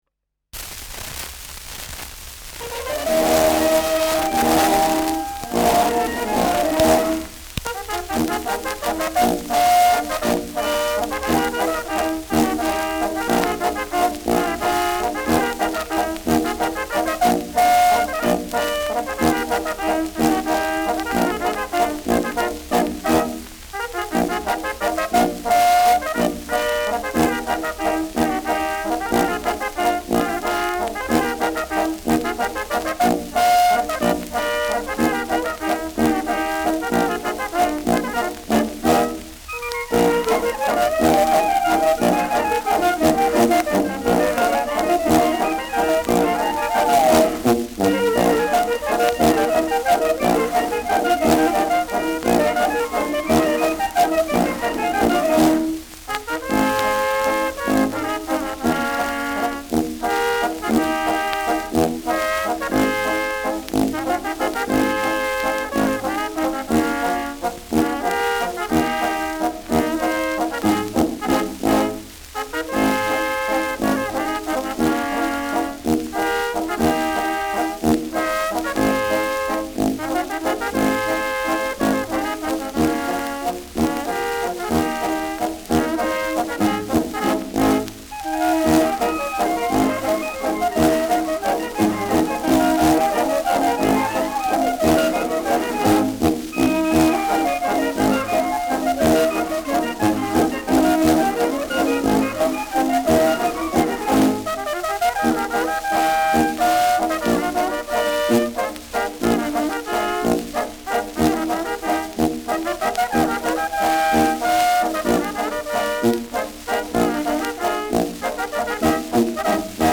Schellackplatte
präsentes Rauschen : präsentes Knistern : gelegentliches „Schnarren“ : leichtes Leiern
Dachauer Bauernkapelle (Interpretation)
Mit Juchzern und am Ende Ausruf „Auf ihr Musikanten!“.